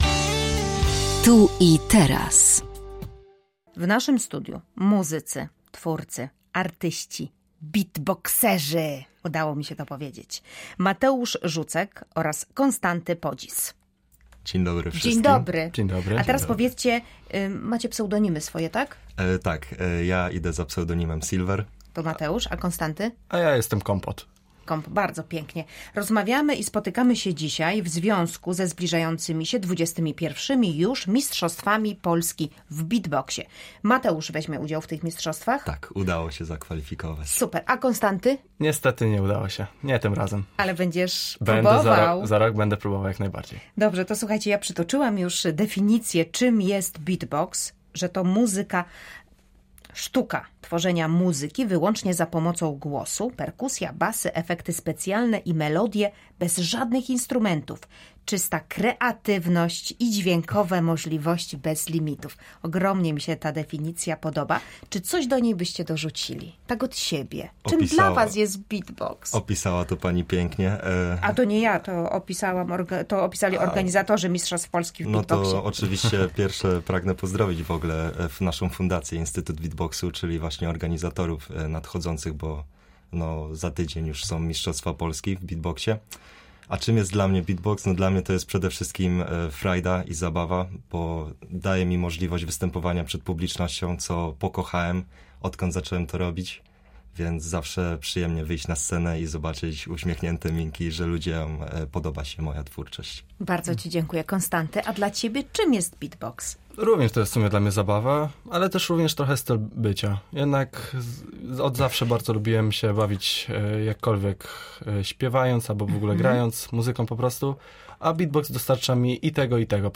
03_07_Tu_i_teraz_beatboxerzy.mp3